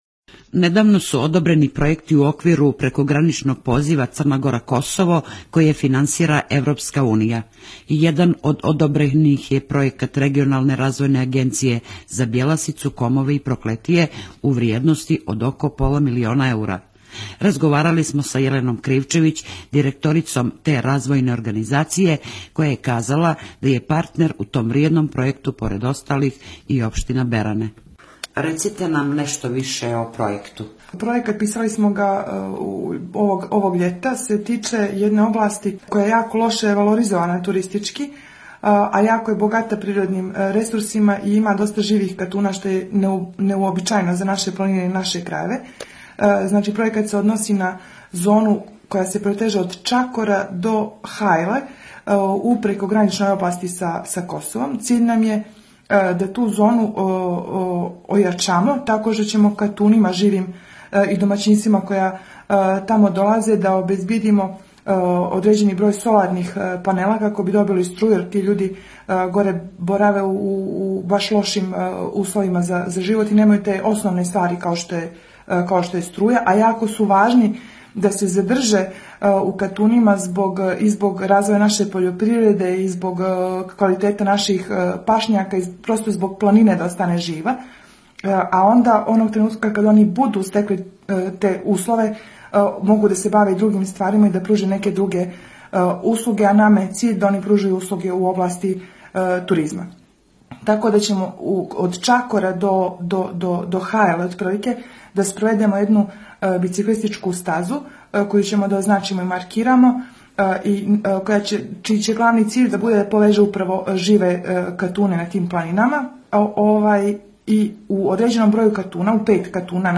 JK-RADIO-BERANE.mp3